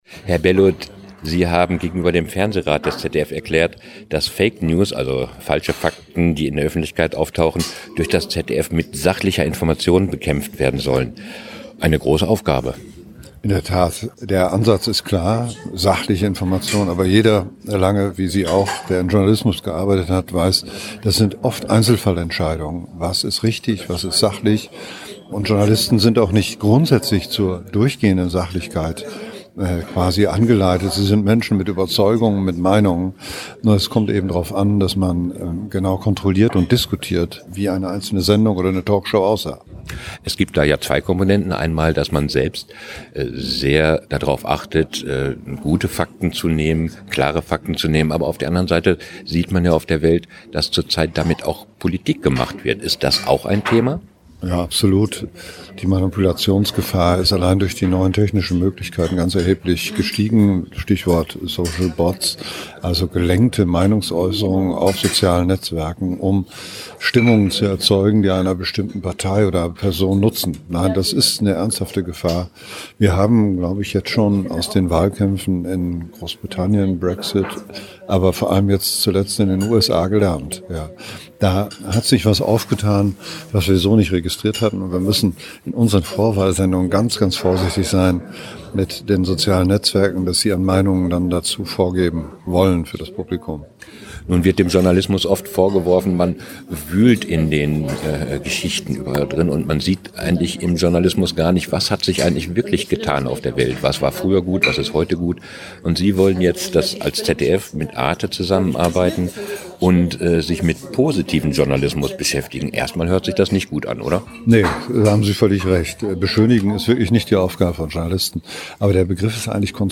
* Dr. Thomas Bellut, ZDF-Intendant
Was: Interview über fake news, constructive journalism
Wo: Mainz, ZDF-Sendezentrum